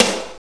NJS SNR 11.wav